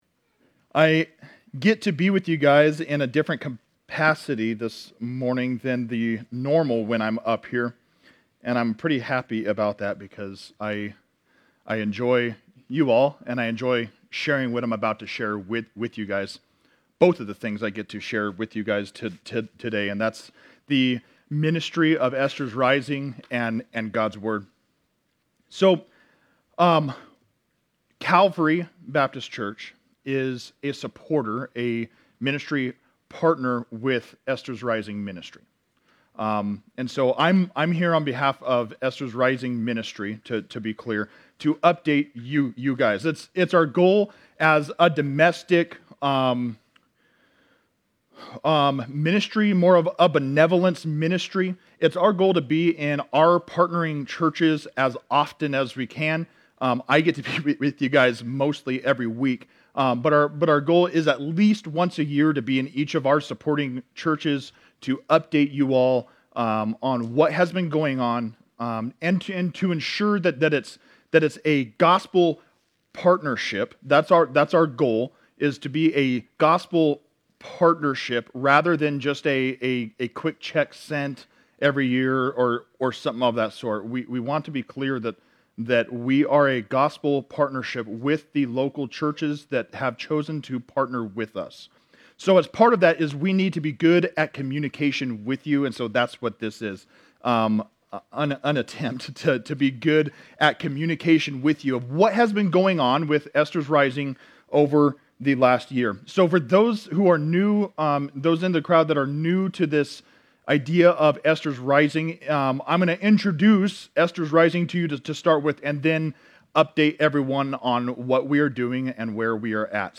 One Off Sermons Passage: Hebrews 10:19-25 Service Type: Morning Service Topics